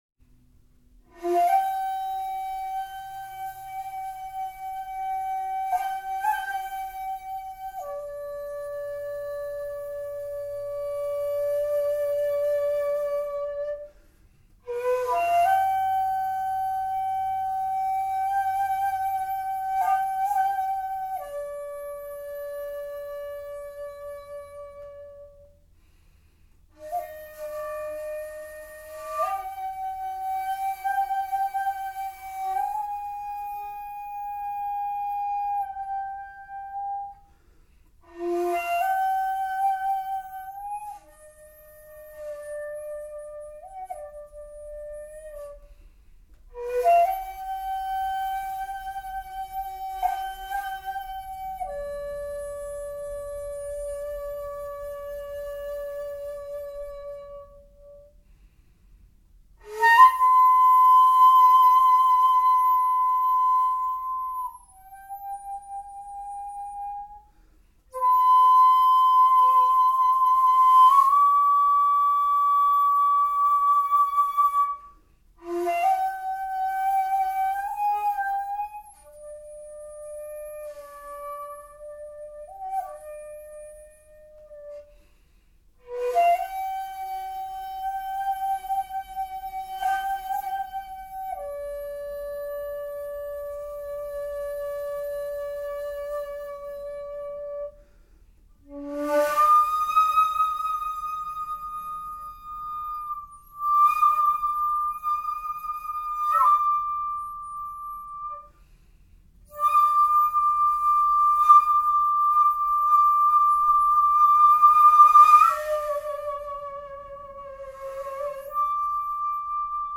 ○尺八本曲・・・地無し管使用
音色が味わい深い。